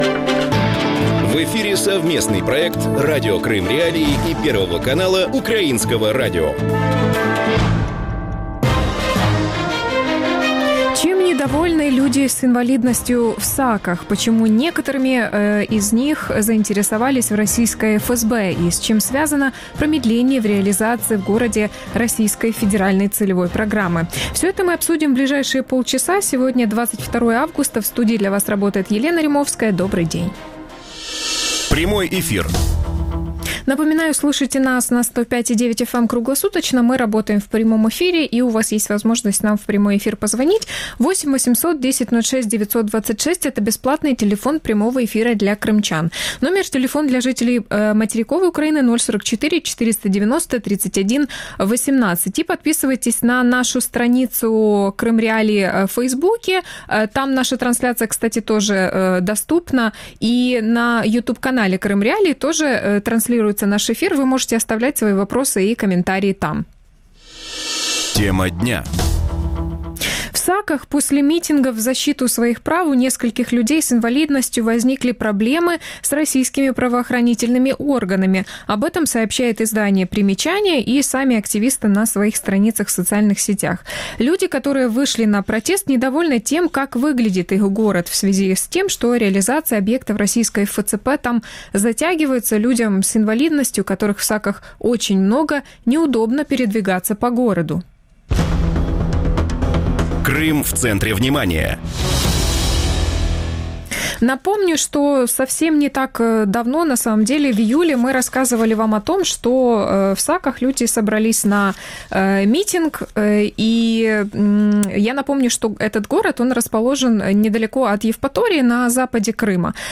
Эдуард Леонов, украинский политик, народный депутат Украины 7-го созыва.